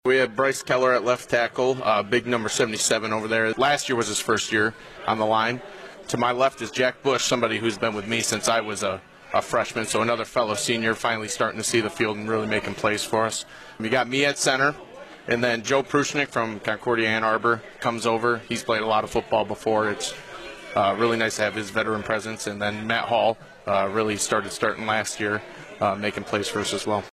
a talk show at Fricker’s in Adrian Monday nights from 7-8pm